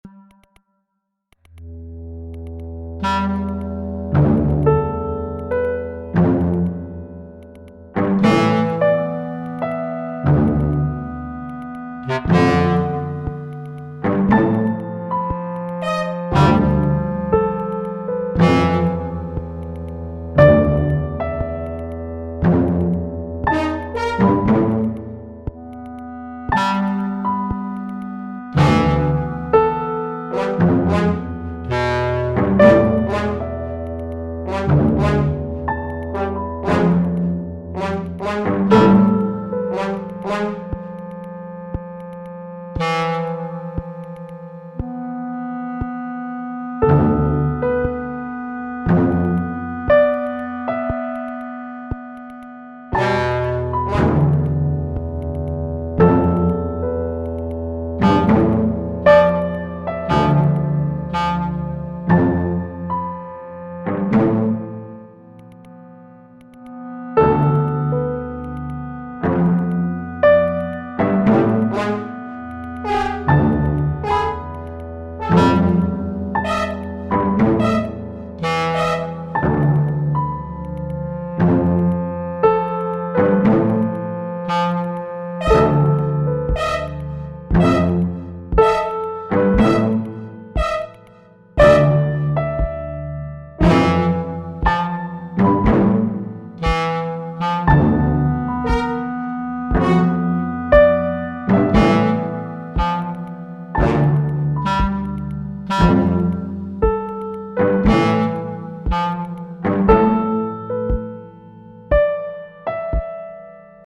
Vivant Classique